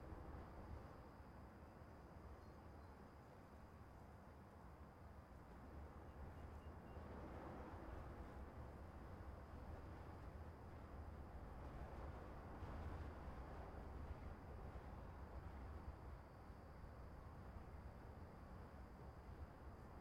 sfx_amb_map_zoomedout_plain.ogg